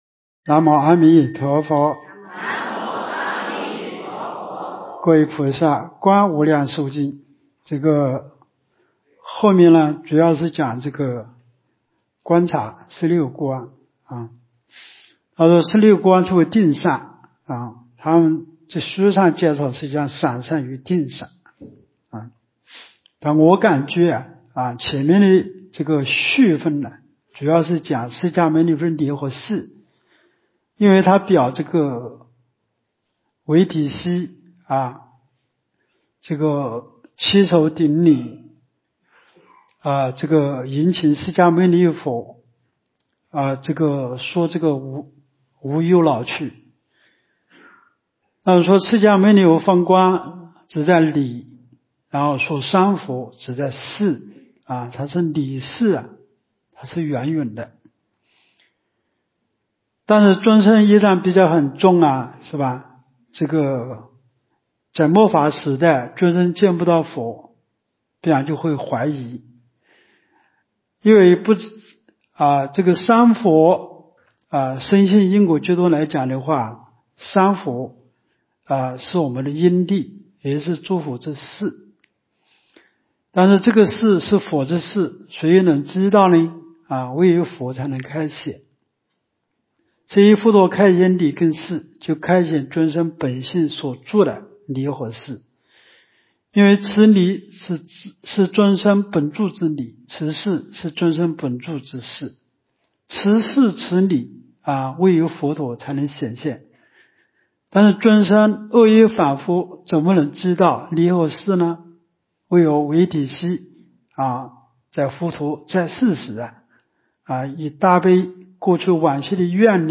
无量寿寺冬季极乐法会精进佛七开示（16）（观无量寿佛经）...